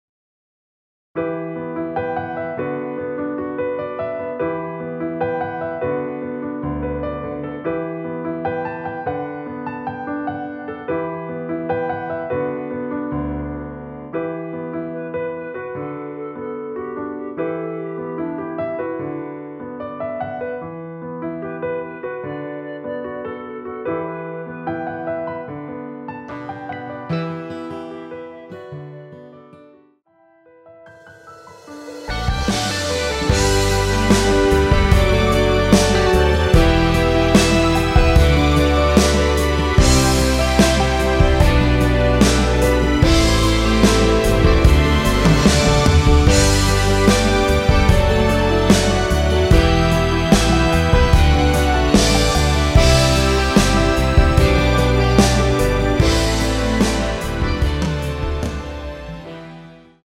원키에서(-7)내린 멜로디 포함된 MR입니다.(미리듣기 확인)
Em
앞부분30초, 뒷부분30초씩 편집해서 올려 드리고 있습니다.
중간에 음이 끈어지고 다시 나오는 이유는